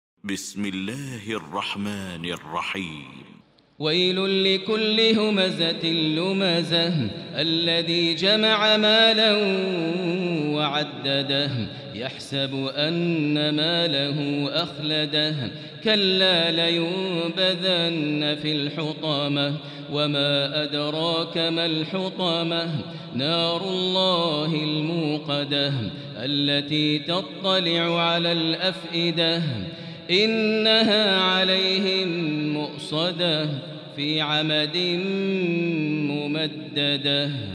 المكان: المسجد الحرام الشيخ: فضيلة الشيخ ماهر المعيقلي فضيلة الشيخ ماهر المعيقلي الهمزة